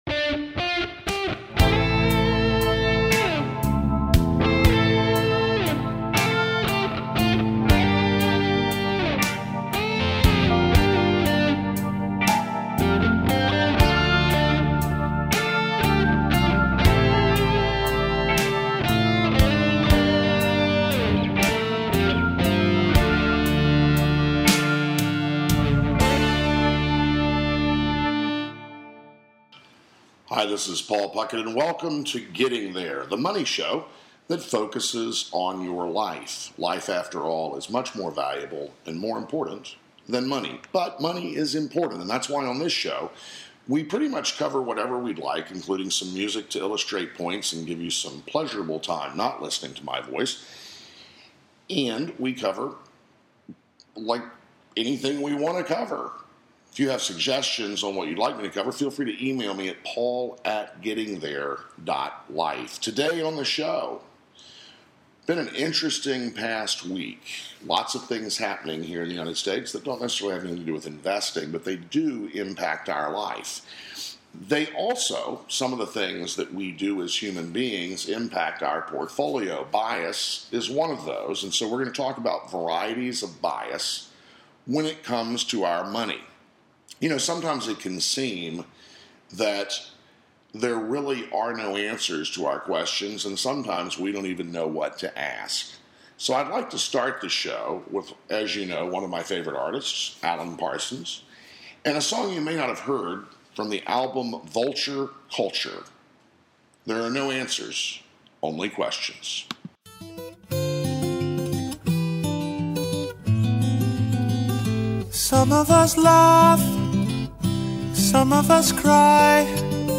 Talk Show
In addition to money issues, the conversation will include gardening, cooking, books, travel, and interviews with guests from the financial field as well as chefs, gardeners, authors, and spiritual leaders.